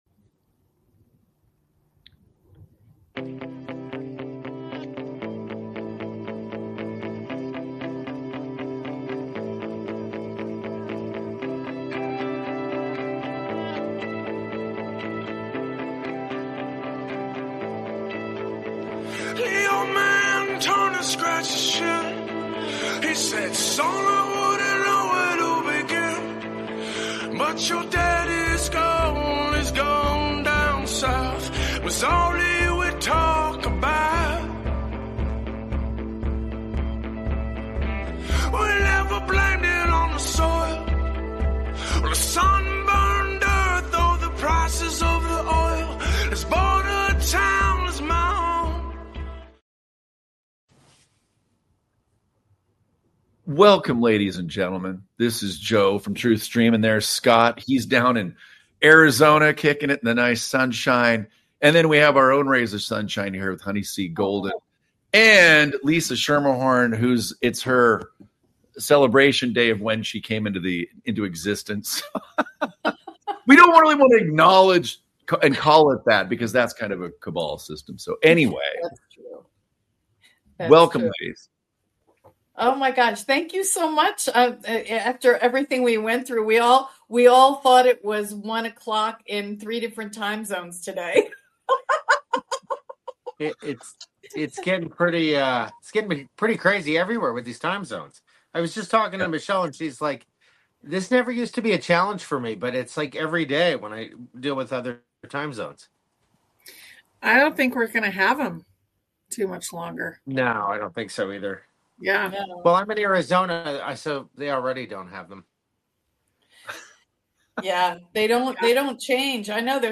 Friends chat about time zones, a special celebration, and bending spoons with their minds! They share a funny story about buying a mini fridge and trusting their gut. They also talk about scams and staying alert.